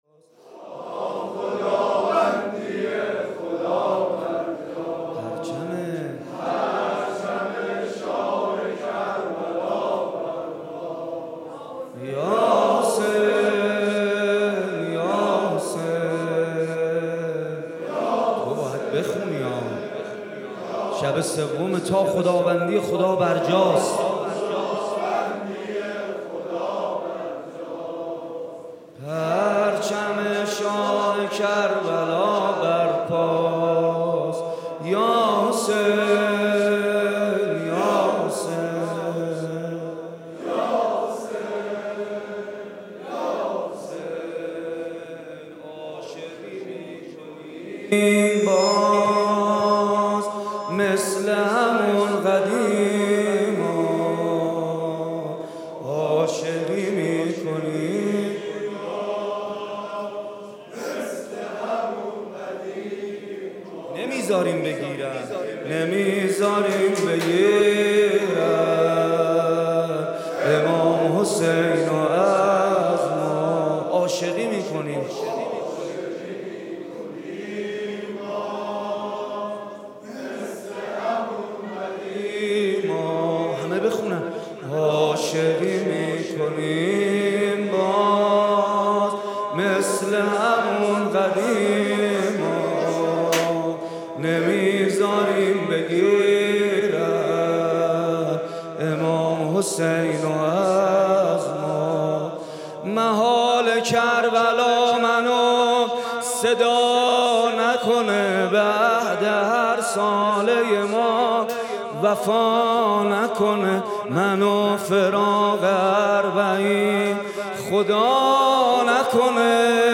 کربلایی محمد حسین پویانفر
محرم 99 - شب سوم - نوحه - تا خداوندی خدا برجاست